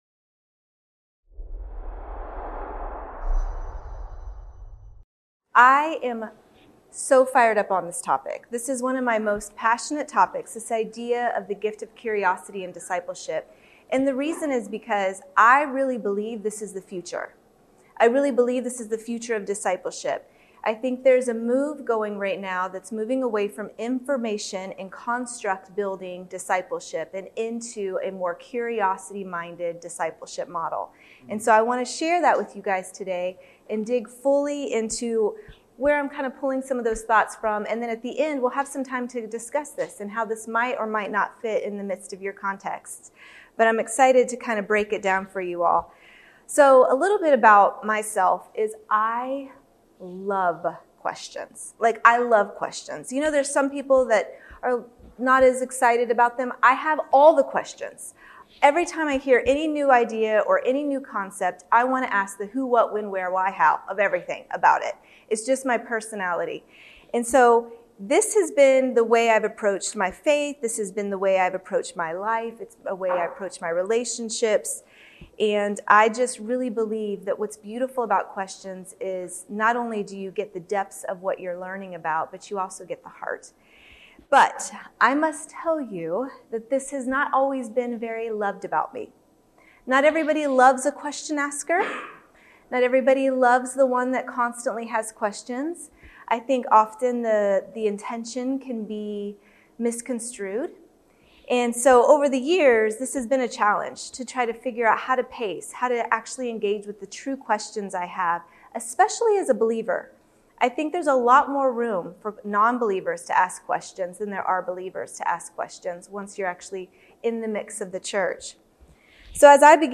Awakening the curiosity that lies within another can be our greatest tool for making disciples. This talk explores the power of questions and how they pave the way toward deeper growth and a vibrant discipleship culture.